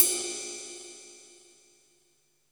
SC RIDE 1.wav